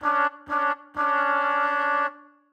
Index of /musicradar/gangster-sting-samples/95bpm Loops
GS_MuteHorn_95-D1.wav